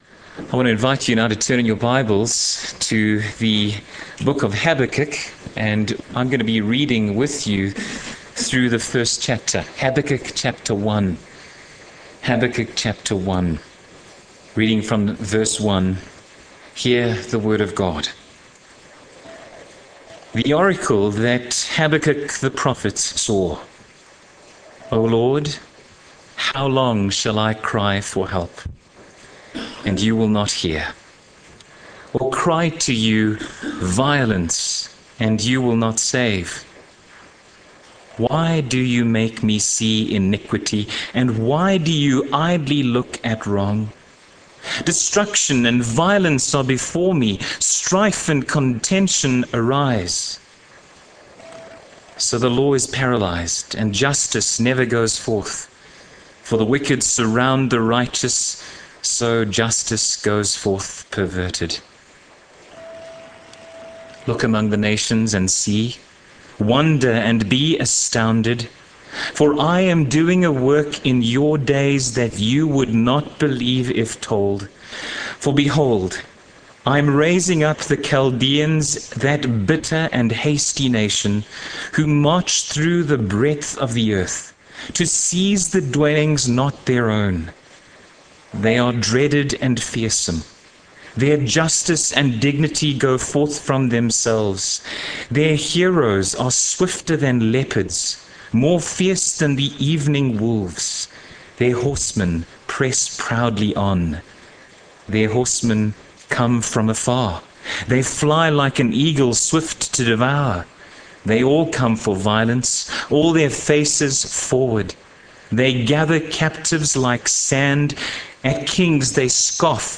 2010 Questions & Answers